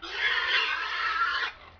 Pianeta Gratis - Audio - Animali
maiale_pig05.wav